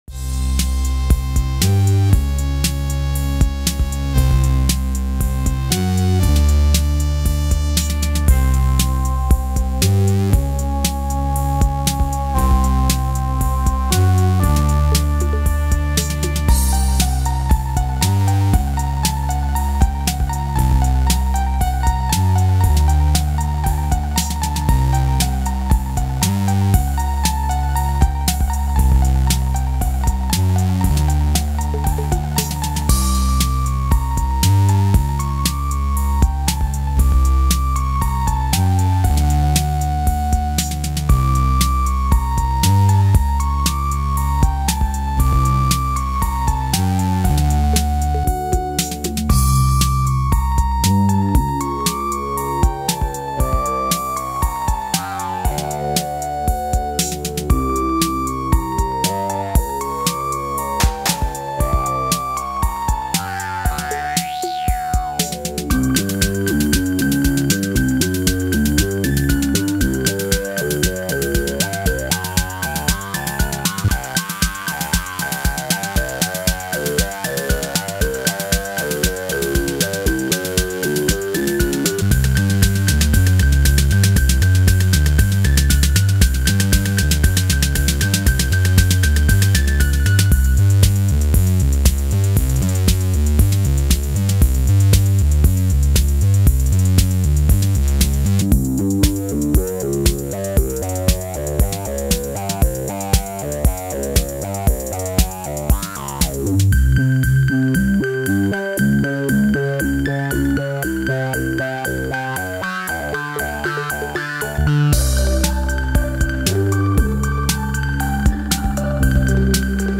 O’course, it came out different, but that’s live analog music for you.
• Lead lines - K2 and 0Coast
• Bass line - Wasp
• Beats - RD-8